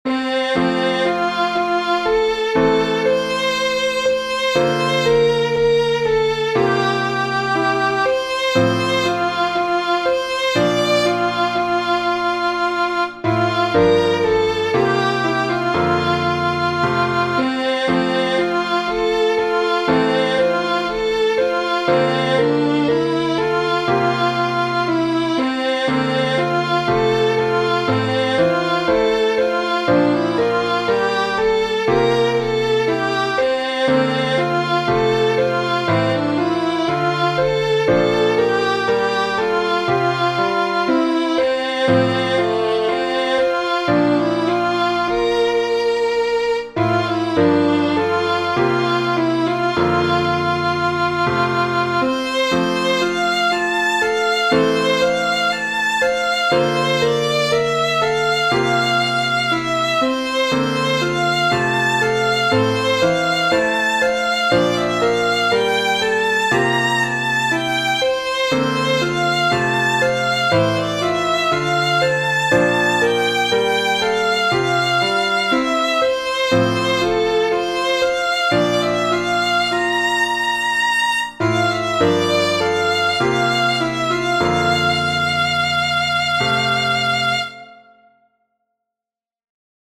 Piano & Violin obligato
Voicing/Instrumentation: Primary Children/Primary Solo
Violin Optional Obbligato/Violin Accompaniment